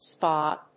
/spάː(米国英語), spɑ:(英国英語)/